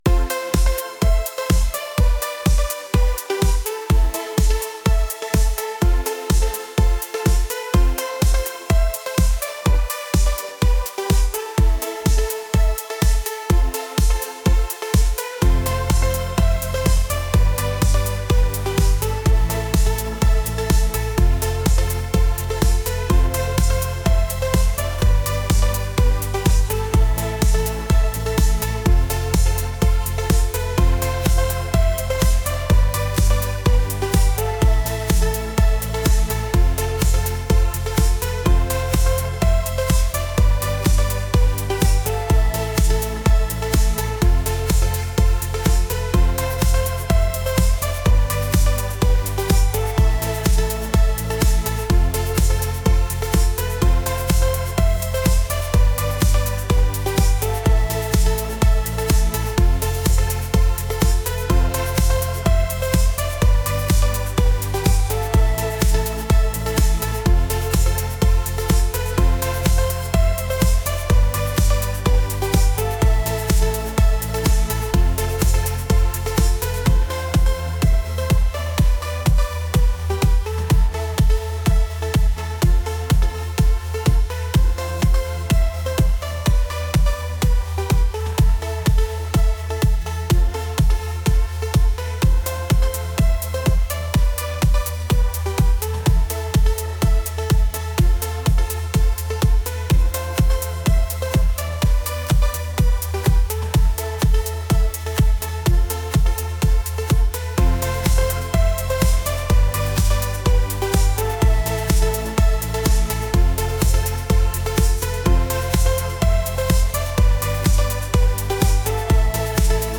pop | energetic | electronic